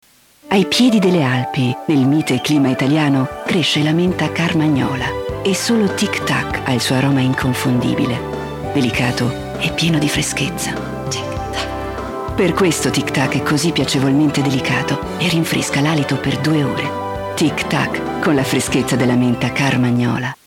Sprecherin italienisch.
Sprechprobe: eLearning (Muttersprache):
female voice over artist italian.